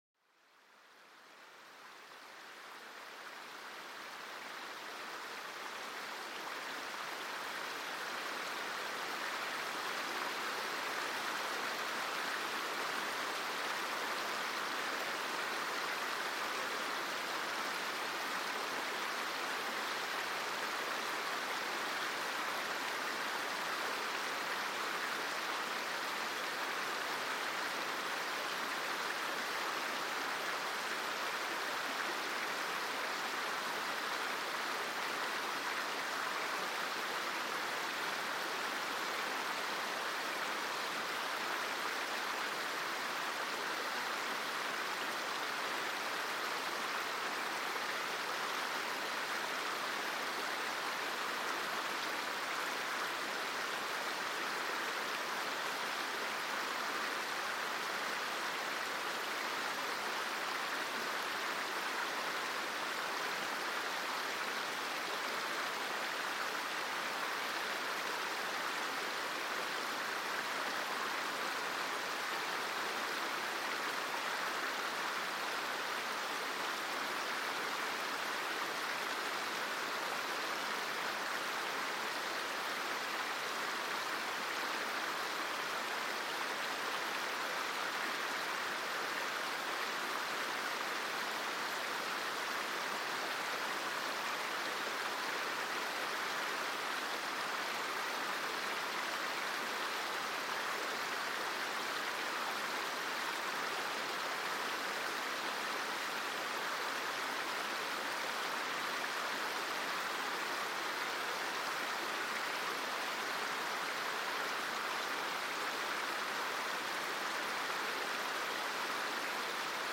Descubre la magia sonora de un arroyo, una sinfonía natural que serpentea a través del bosque. Cada gota cuenta una historia, mezclándose en un suave susurro que calma la mente y revitaliza el alma.